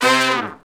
Index of /90_sSampleCDs/Roland LCDP06 Brass Sections/BRS_R&R Horns/BRS_R&R Falls